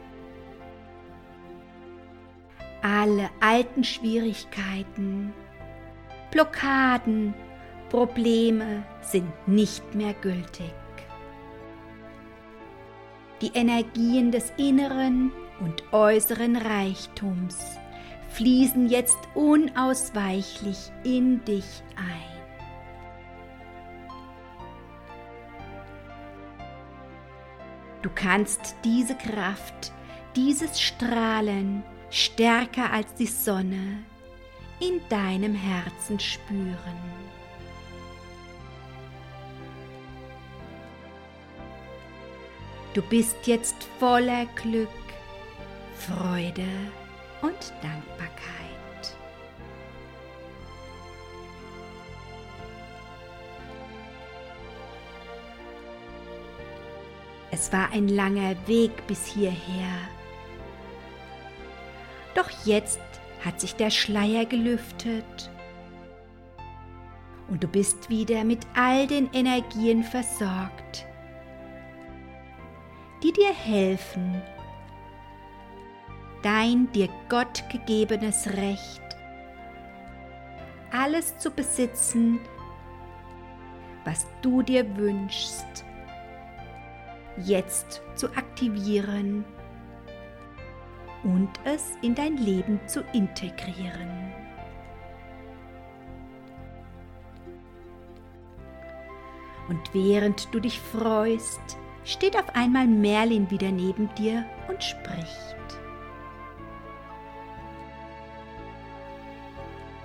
Eine schamanische Reise mit Hintergrundmusik (gemafrei) - mit Einweihung:
Sie erhalten diese schamanische Reise mit Hintergrundmusik.
Diese geführte Meditation ist kein heilkundlicher Beistand im Sinne des BGB und anderer Gesetzesteile.